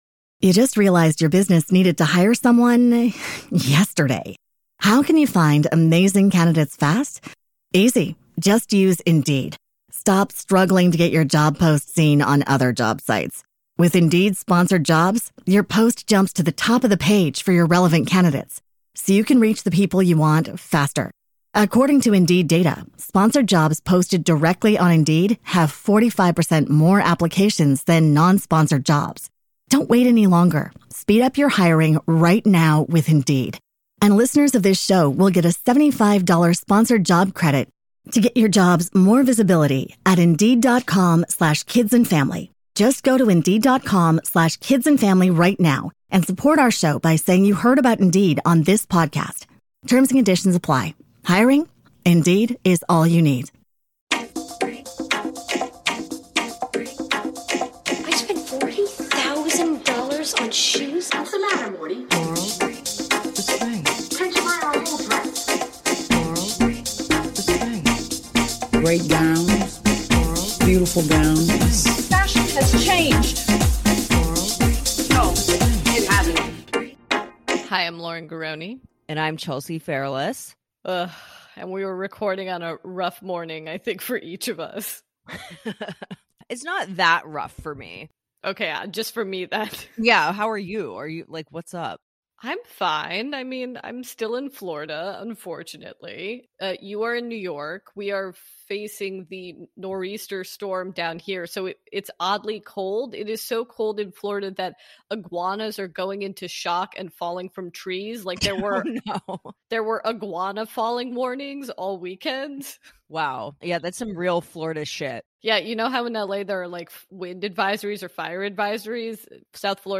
On this week's very special episode, the ladies take your calls. Topics include: open marriages, Che being a terrible weed representative, New York winters, lost plot lines, is And Just Like That MPK's Twin Peaks (?!?), continuity errors, Charlotte's Brita, a call from Steve Brady himself, and SO MUCH MORE!